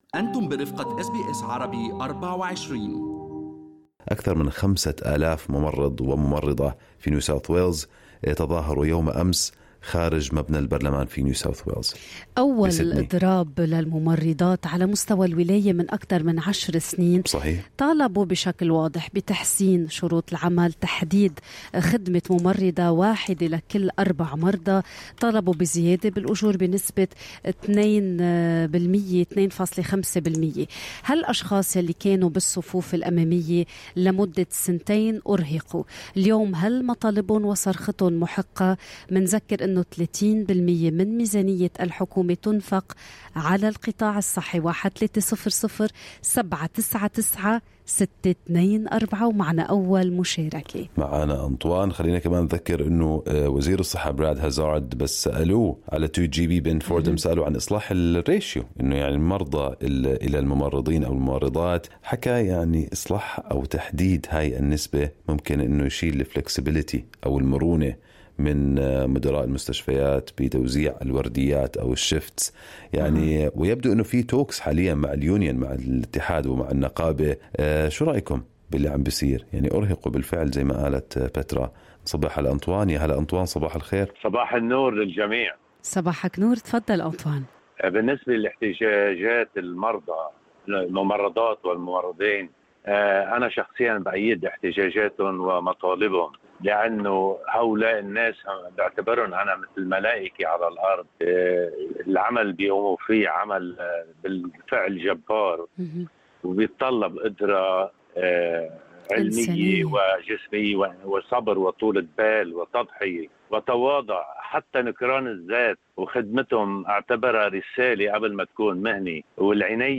للاستماع إلى كامل تعليقاتكم واتصالاتكم الهاتفية حول الموضوع، برجاء الضغط على الملف الصوتي المرفق أعلاه.